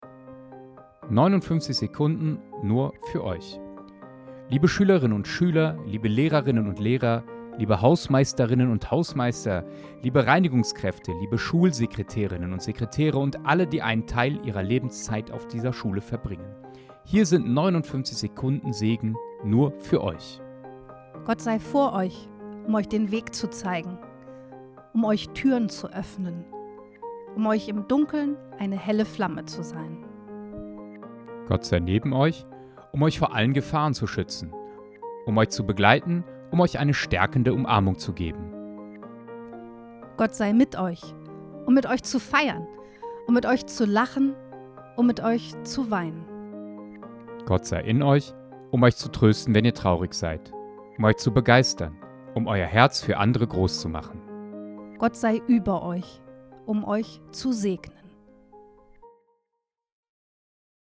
Digitaler Gottesdienst zum Schuljahresende - Nelly-Sachs-Gymnasium
59_sekunden_segen.mp3